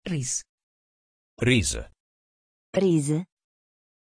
Aussprache von Reese
pronunciation-reese-it.mp3